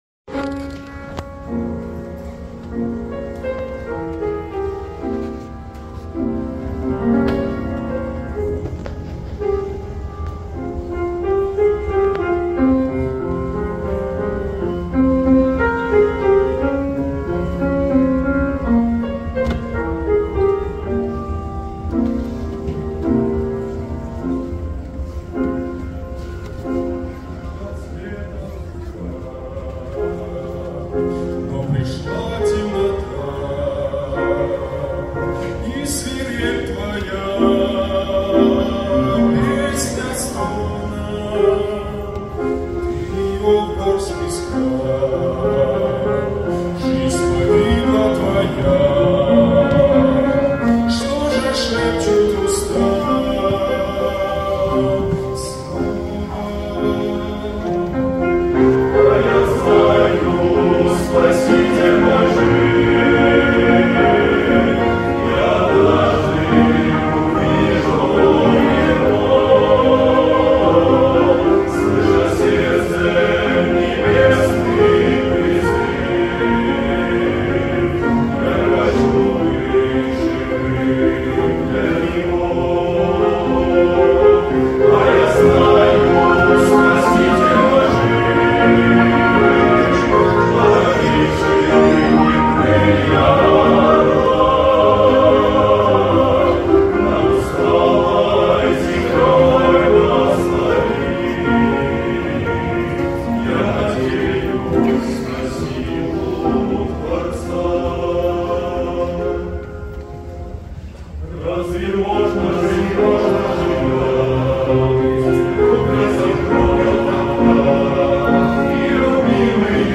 песня
87 просмотров 122 прослушивания 2 скачивания BPM: 79